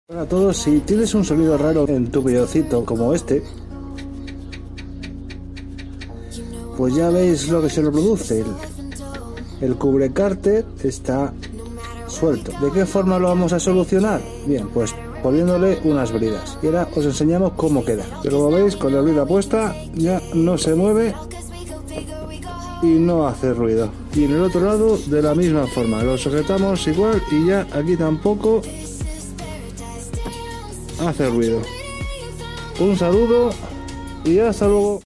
Ruido en el cubre carter sound effects free download
Ruido en el cubre carter del Peugeot 208